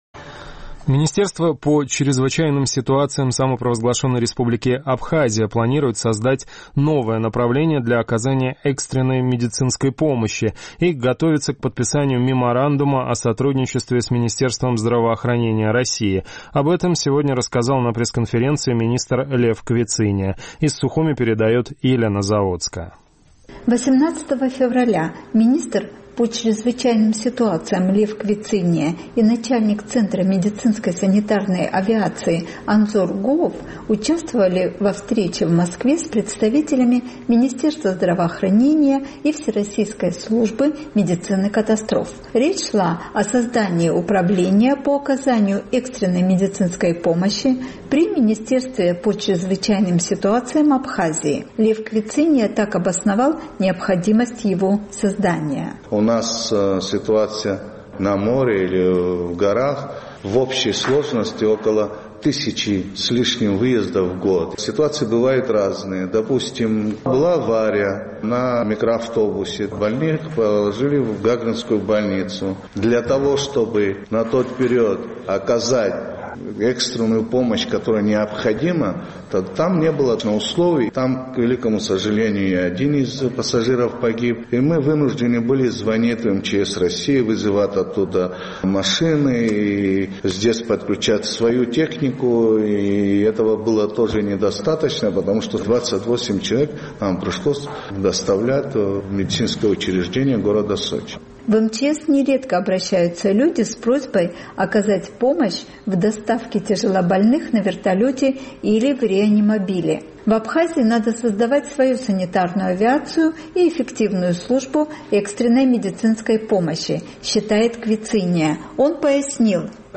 Министерство по чрезвычайным ситуациям Абхазии планирует создать новое направление для оказания экстренной медицинской помощи и готовится к подписанию меморандума о сотрудничестве с Министерством здравоохранения России. Об этом рассказал на пресс-конференции министр Лев Квициния.